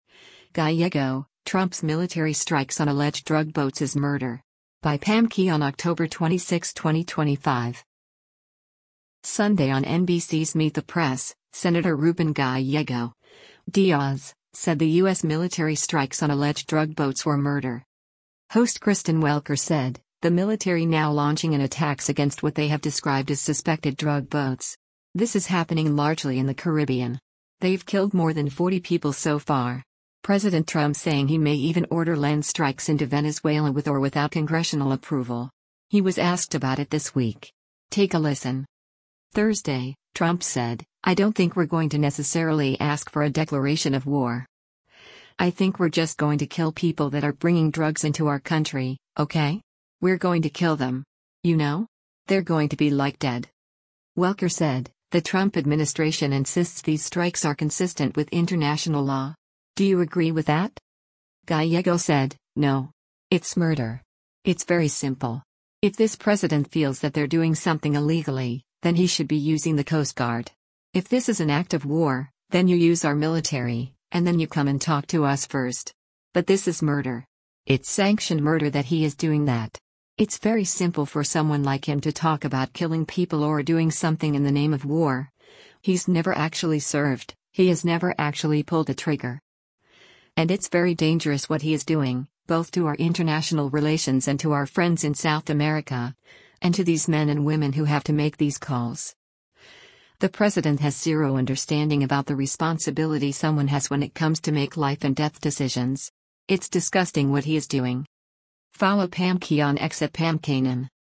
Sunday on NBC’s “Meet the Press,” Sen. Ruben Gallego (D-AZ) said the U.S. military strikes on alleged drug boats were “murder.”